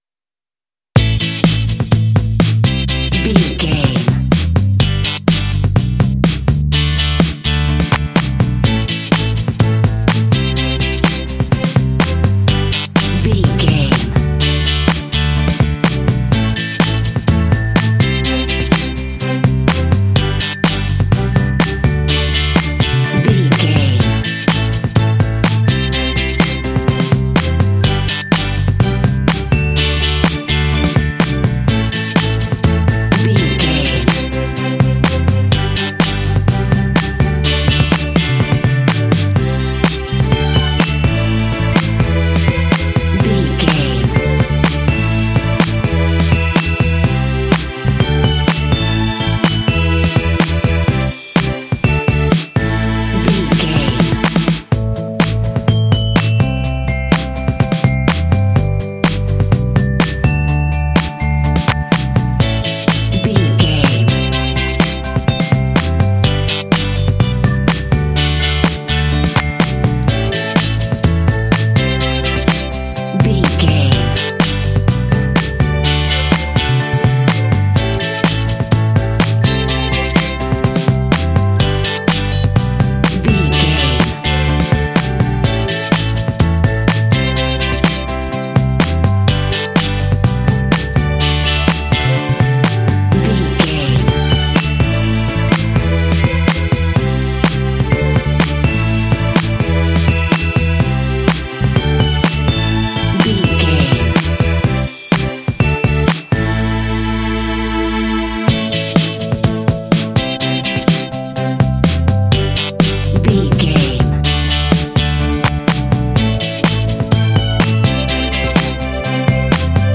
Aeolian/Minor
optimistic
happy
bright
strings
drums
bass guitar
electric guitar
pop
contemporary underscore
rock
indie